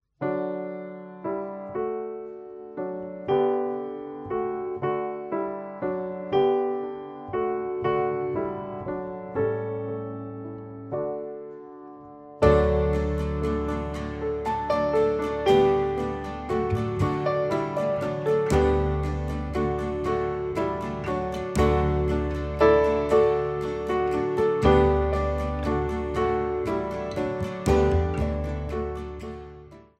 für eine oder zwei Sopranblockflöten
Besetzung: 1-2 Sopranblockflöten